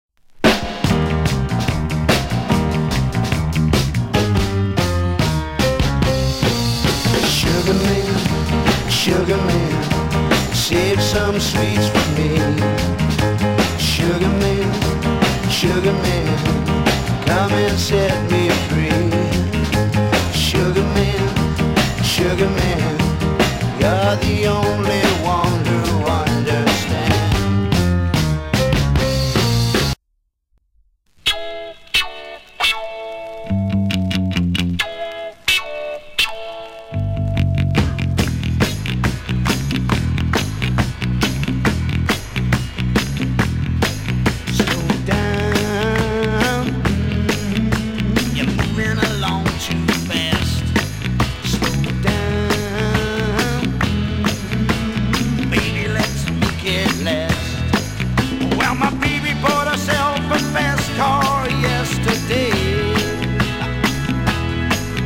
(税込￥3850)   FUNKY POP